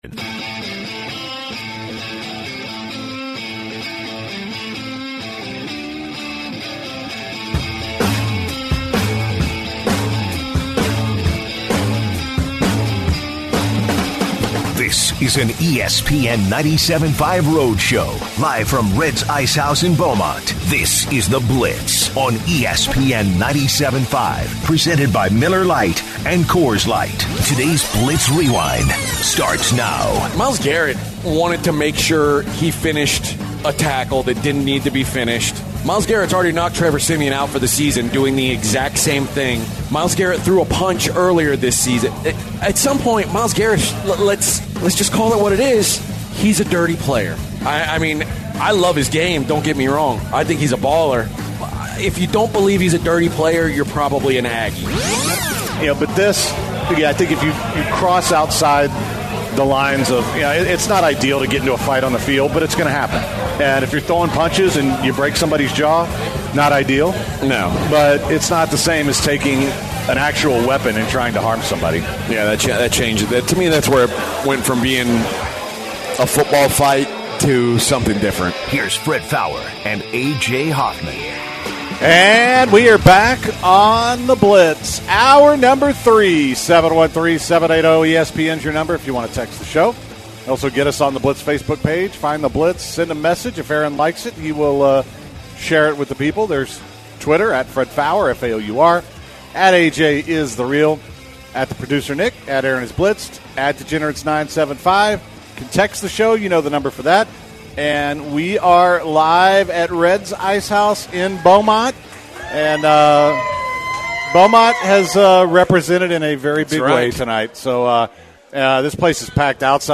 The guys kick off the final hour of the show reacting to Carmelo Anthony signing with the Trailblazers and continue their discussion on the Myles Garrett incident.